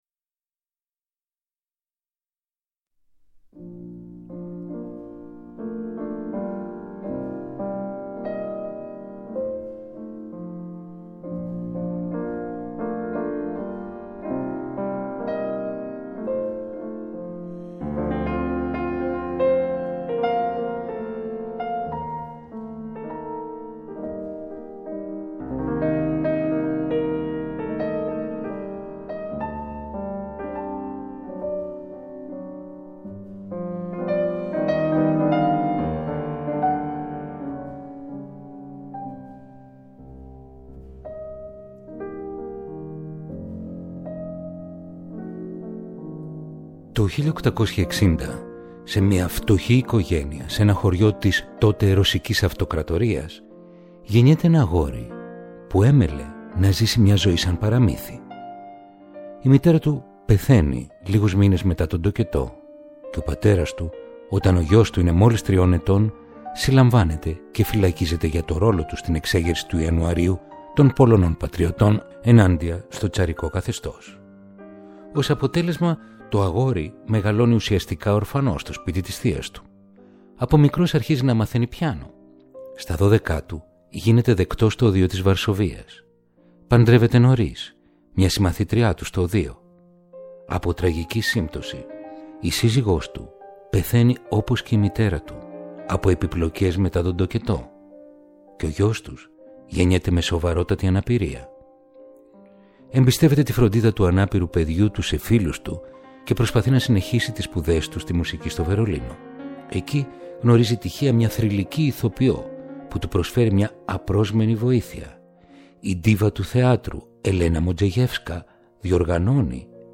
Ρομαντικά κοντσέρτα για πιάνο – Επεισόδιο 31ο